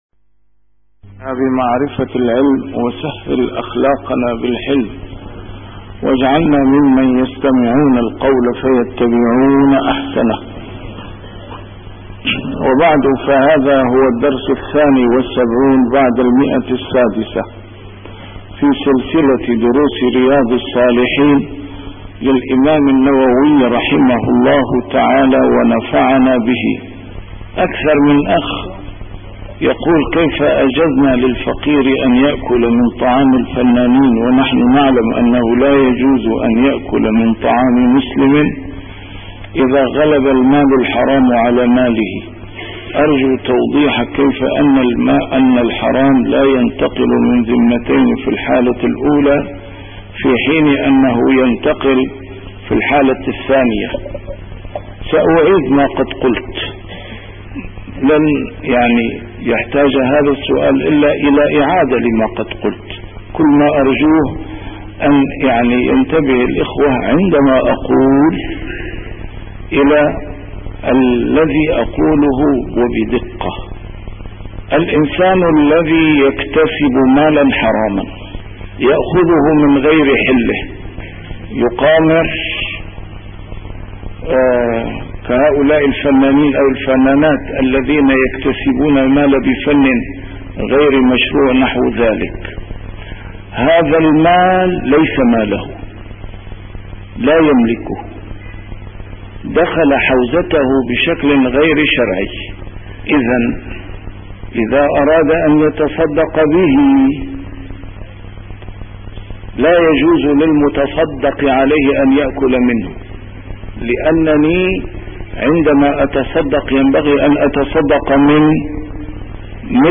A MARTYR SCHOLAR: IMAM MUHAMMAD SAEED RAMADAN AL-BOUTI - الدروس العلمية - شرح كتاب رياض الصالحين - 672- شرح رياض الصالحين: جواز الاستلقاء على القفا